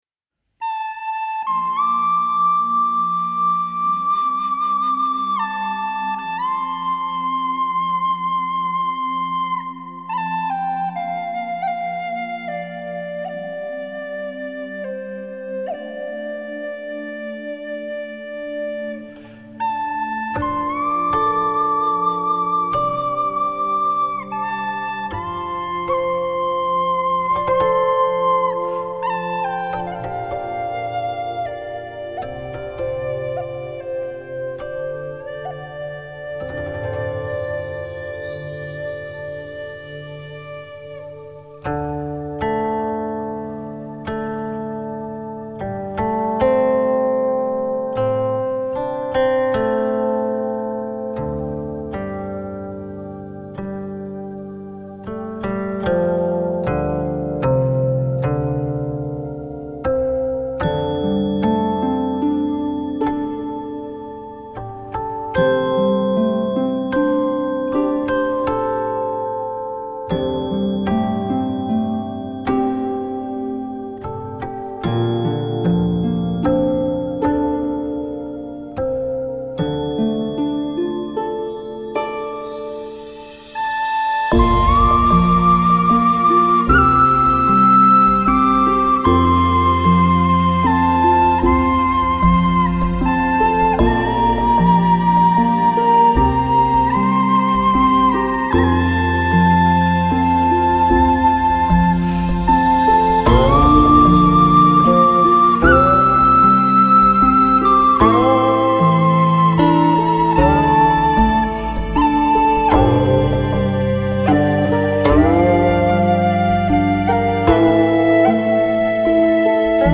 埙/箫/班苏里/哨笛/竖笛
古琴
大提琴
中阮
笛箫声声，将寂静之音吹入心灵，